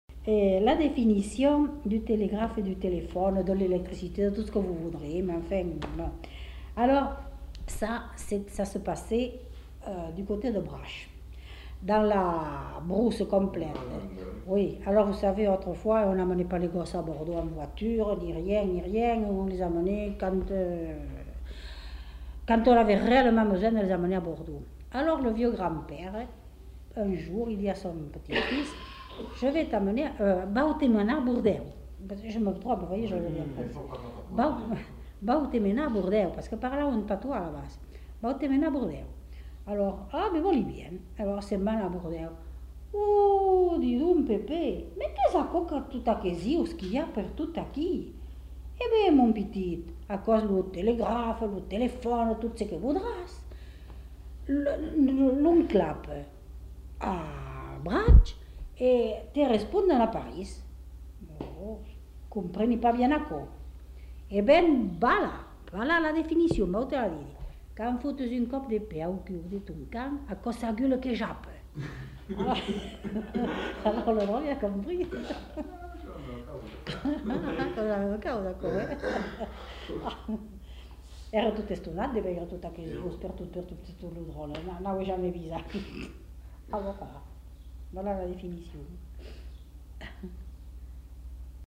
Lieu : Moulis-en-Médoc
Genre : conte-légende-récit
Type de voix : voix de femme
Production du son : parlé